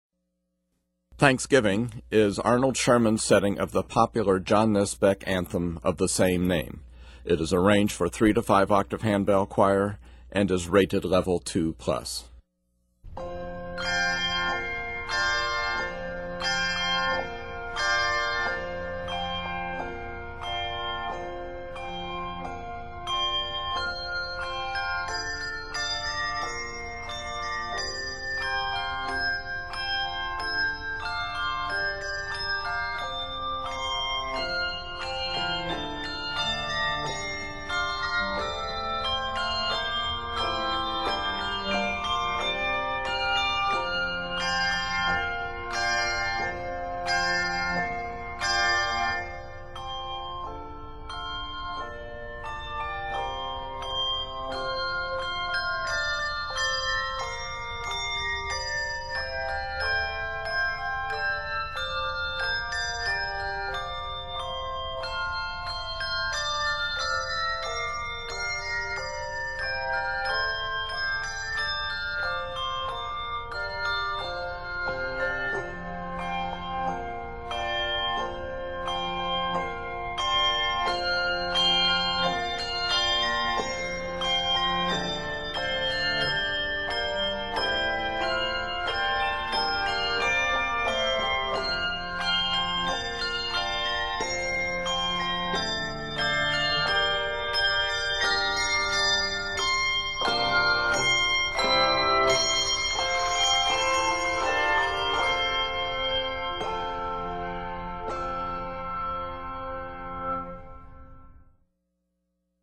Can use handbell arrangement to accompany choral anthem
Octaves: 3-5